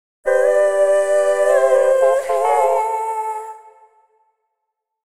各エフェクトの Mix と、Wet音だけを抽出したものです。
ボーカル・ホール系のプリセットを、ほぼそのまま AUX に置き、リターン量が同じになるように設定。